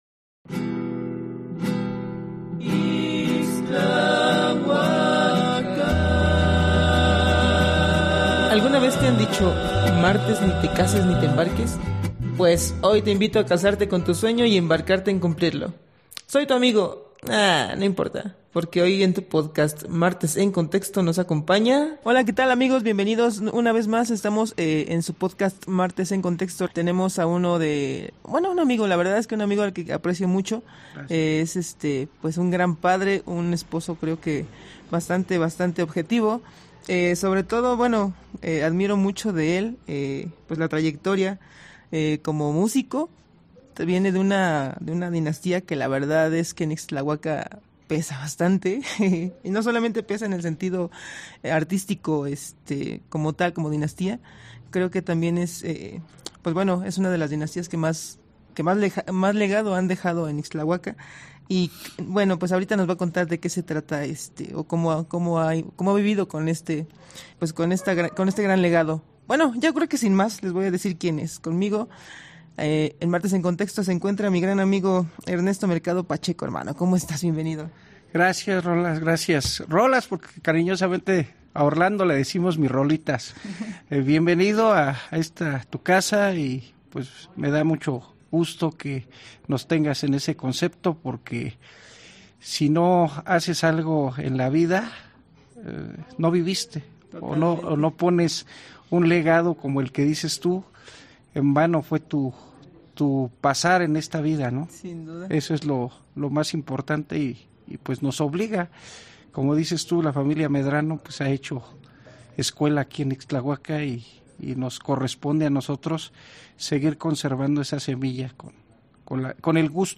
Locación: UNALOME.